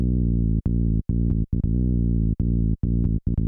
Index of /90_sSampleCDs/Best Service ProSamples vol.54 - Techno 138 BPM [AKAI] 1CD/Partition C/SHELL CRASHE
NASTY BASS-L.wav